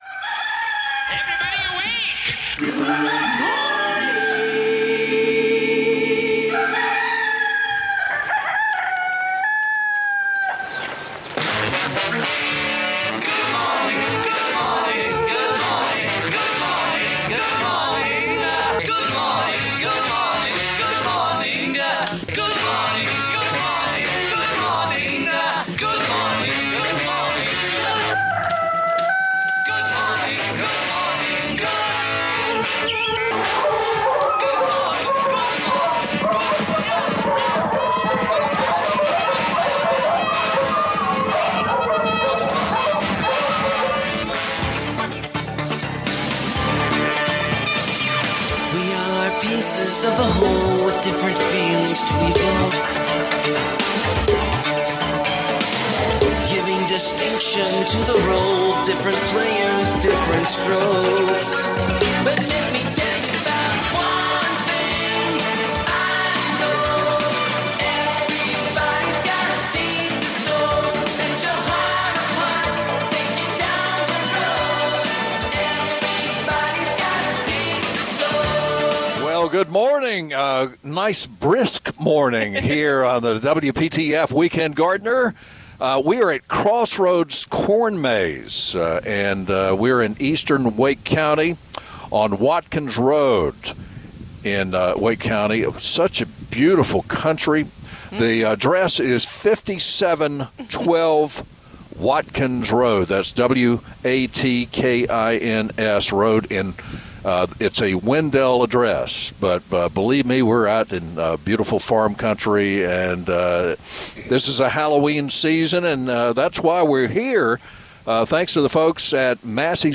Weekend Gardener - Live from Crossroads Corn Maze, Wendell (Part 1 Of 3)